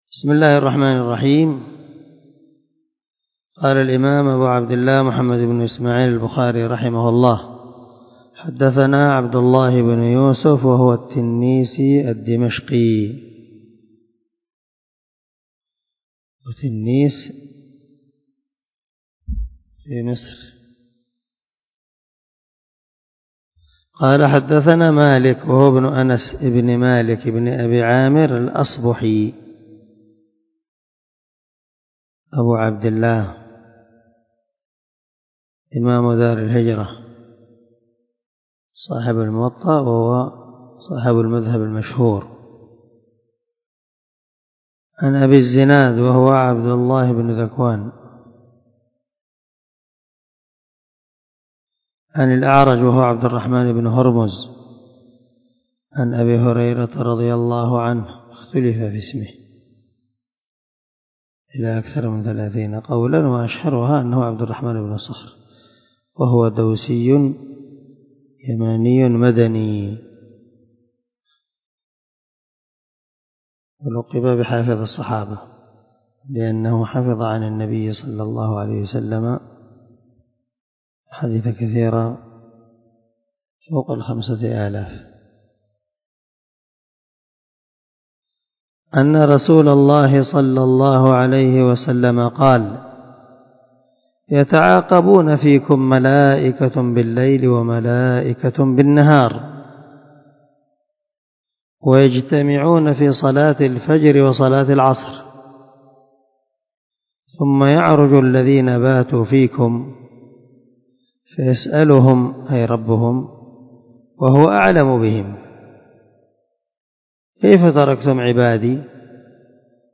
392الدرس 22 من شرح كتاب مواقيت الصلاة حديث رقم ( 555 ) من صحيح البخاري
دار الحديث- المَحاوِلة- الصبيحة.